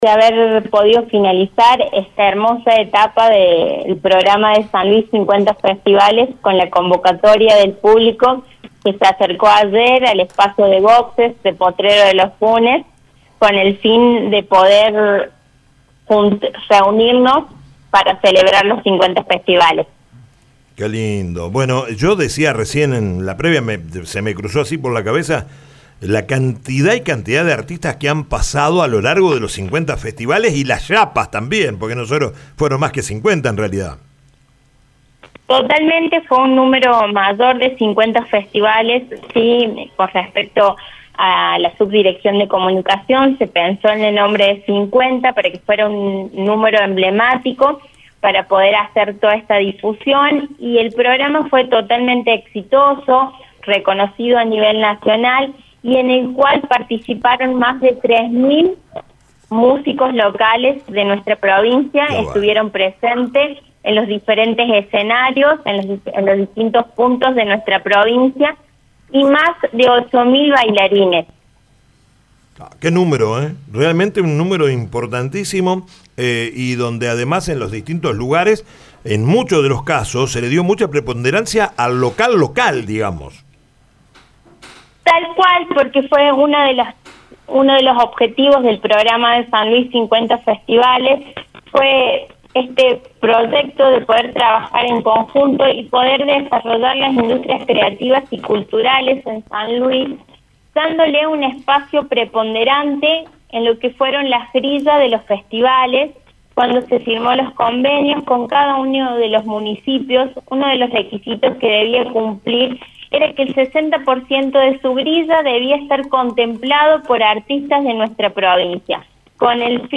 En un balance exhaustivo realizado en “El Show de la Tarde”, la directora de Cultura, Nadia Ybarra, reveló cifras contundentes del ciclo: participaron más de 11.000 artistas puntanos entre músicos y bailarines.